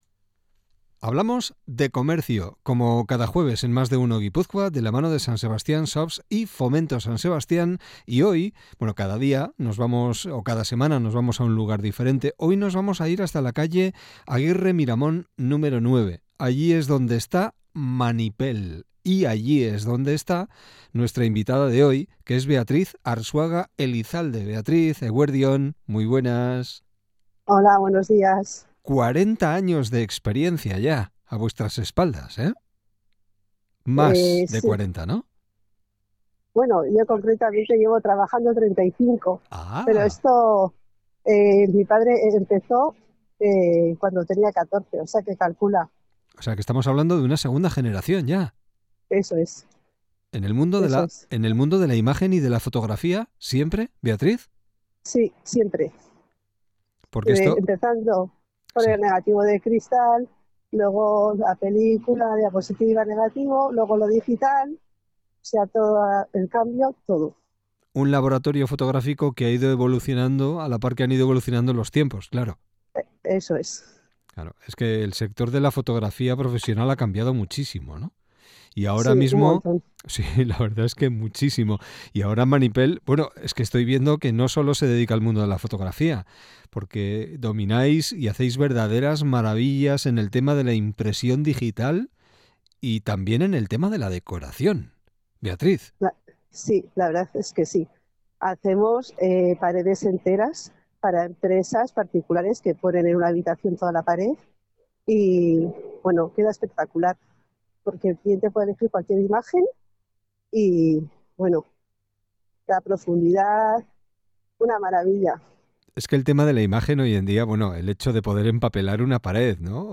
ELKARRIZKETA